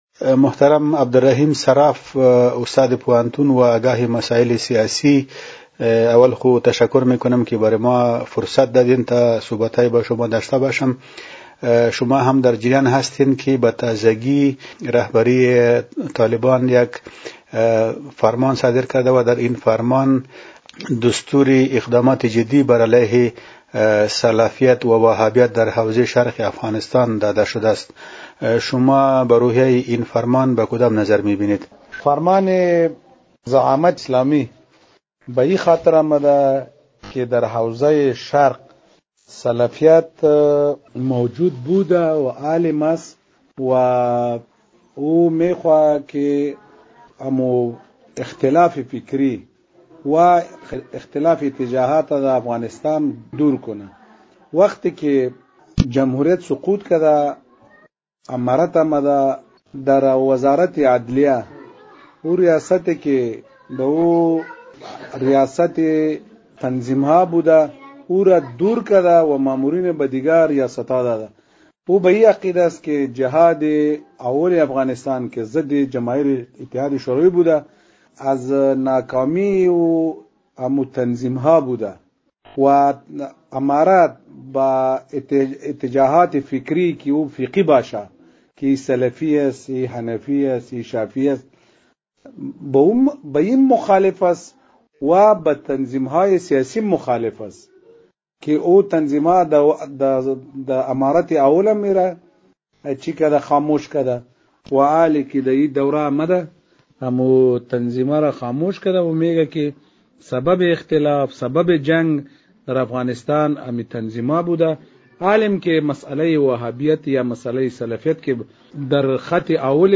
خبر / مصاحبه